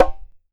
Conga HSlap.WAV